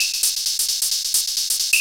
DS 132-BPM A4.wav